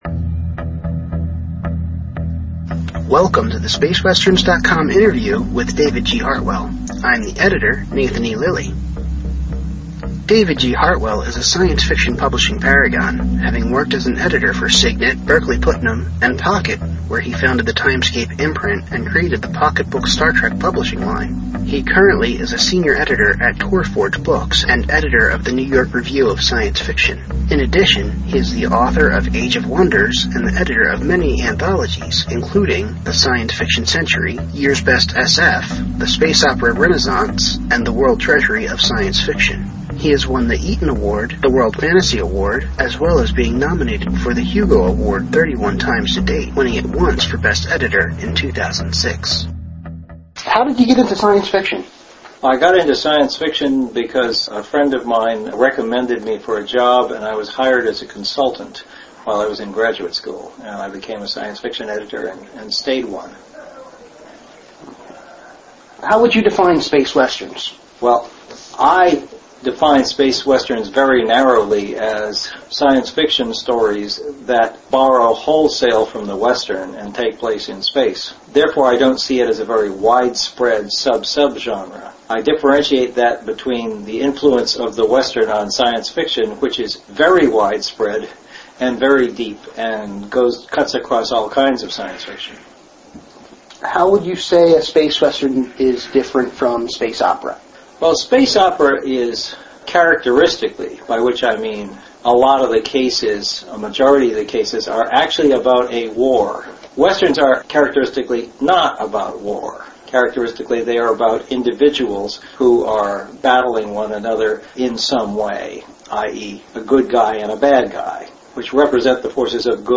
Mr. Hartwell was kind enough to give us an interview on the topic of Space Westerns, at Balticon 41.